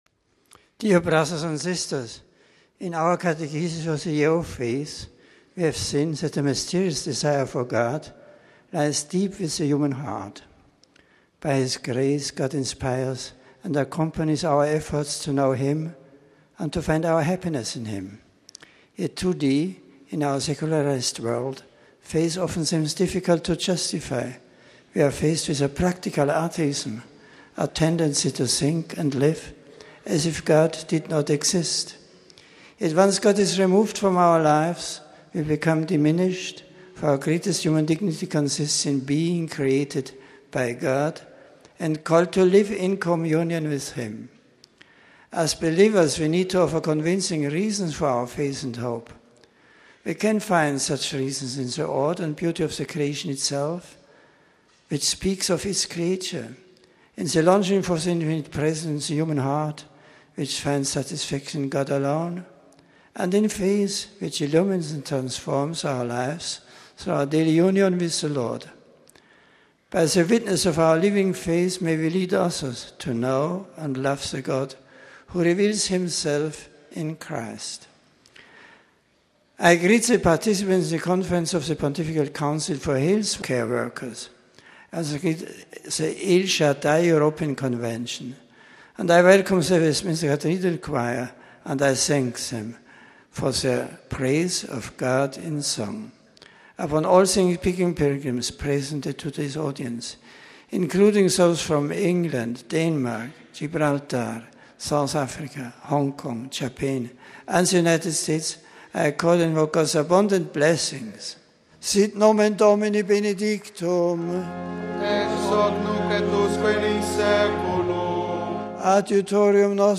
The general audience of Nov 14 was held indoors in the Vatican’s Paul VI audience hall. It began with aides reading a passage from the First Letter of Peter in several languages. After an aide greeted the Pope on behalf of the English speaking pilgrims, Pope Benedict spoke in English.